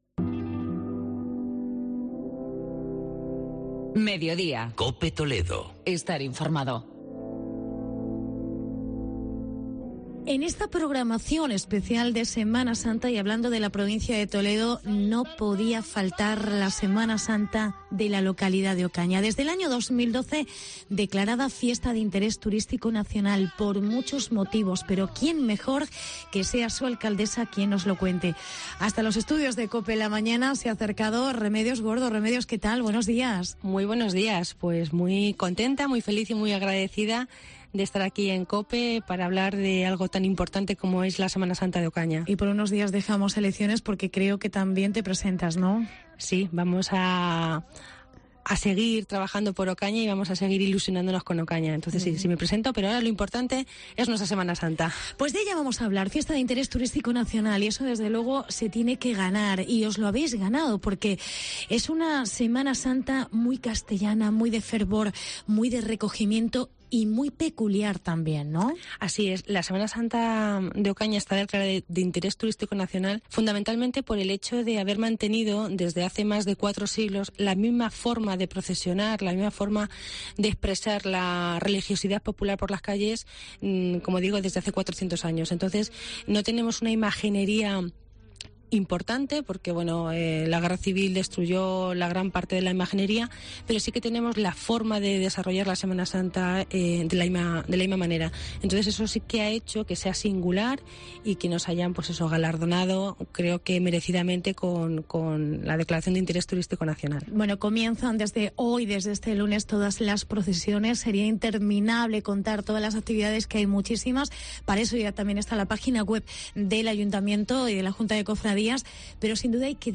Semana Santa en Ocaña. Entrevista con la alcaldesa Remedios Gordo